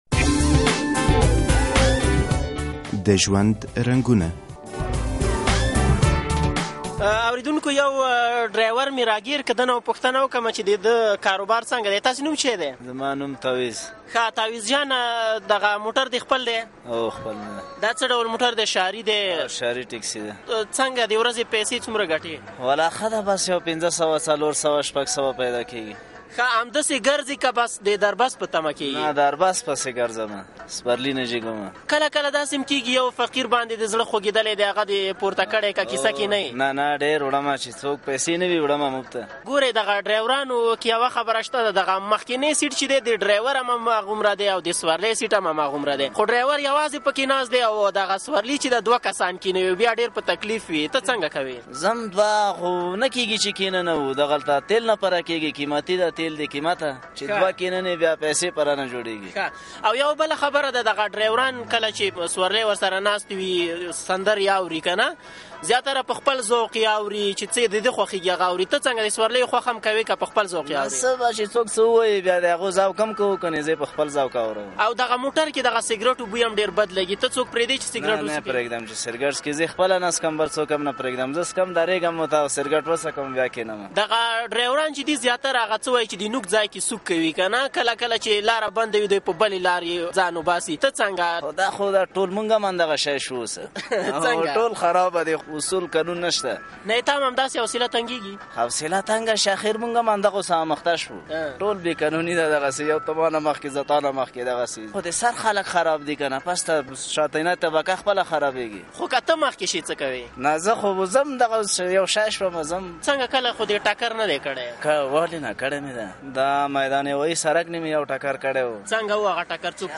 د ژوند په رنګونو کې مو دا ځل له یوه ښاري موټر چلونکي سره مرکه کړې او نوموړی وايي جې له ژونده ډیر راضي دی او په ورځ کې ۵۰۰افغانۍ ګټي او ښه روزګار يي دی.